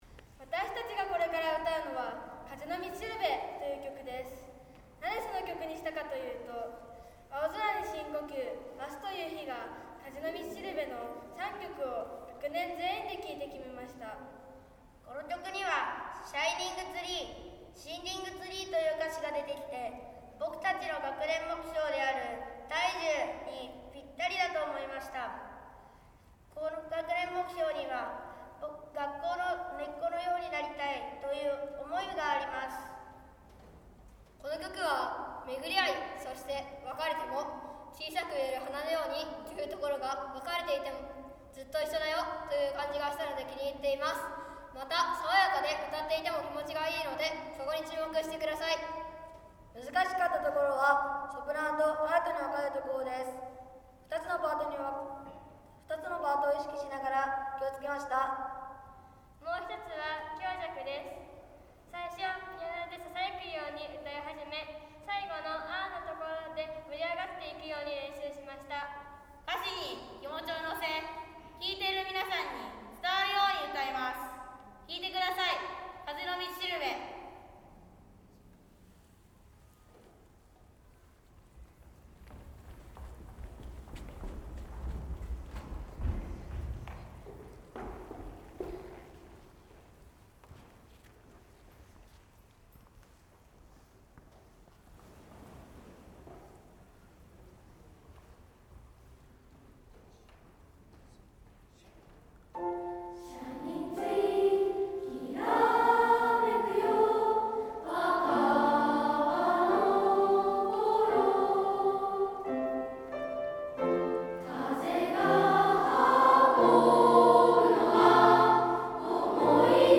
★6年生の歌声をお届けします - 本郷台小学校
今年度も、全校で集まっての音楽朝会は出来ませんが、音楽の時間に体育館で録音した各学年の歌声を、順番にテレビ朝会で紹介する、という形の音楽朝会を行うこととしました。